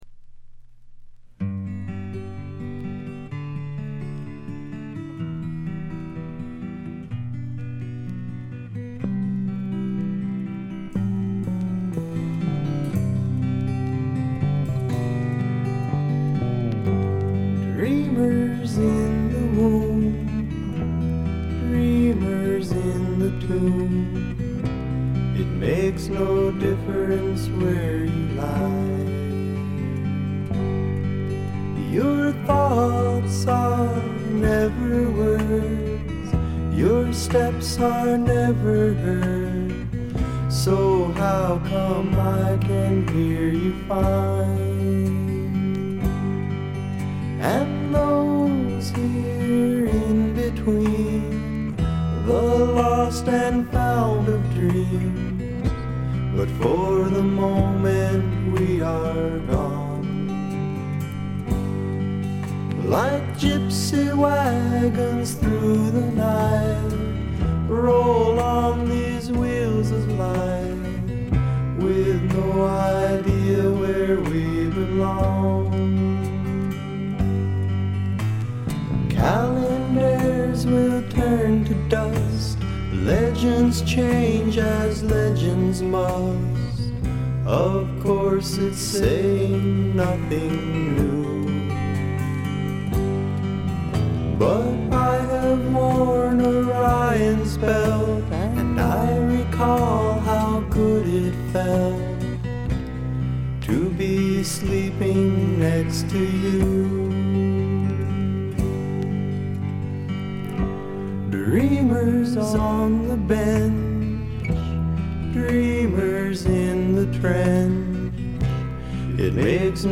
軽微なバックグラウンドノイズ、チリプチ少し。
試聴曲は現品からの取り込み音源です。